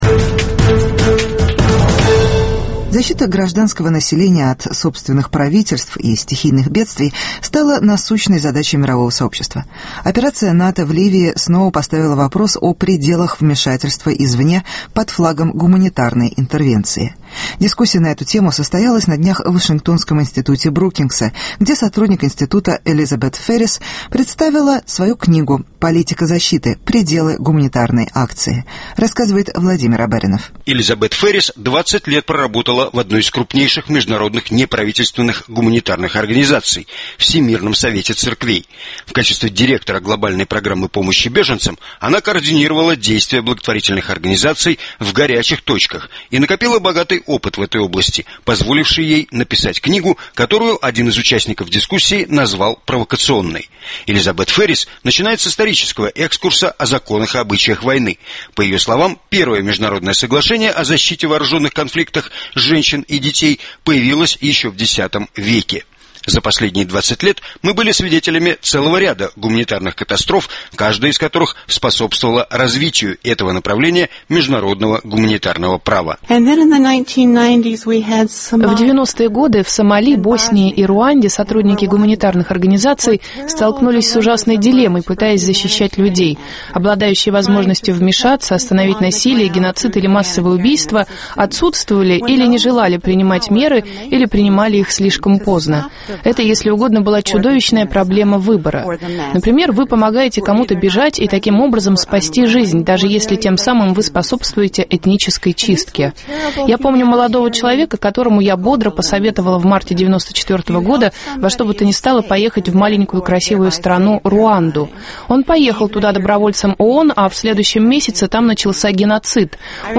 Пределы гуманитарной акции (Дискуссия в вашингтонском институте Брукингса)